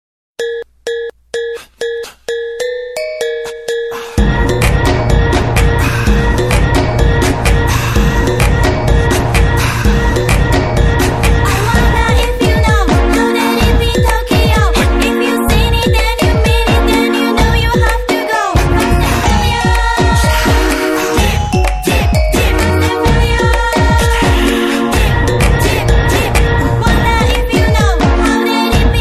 • Bollywood Ringtones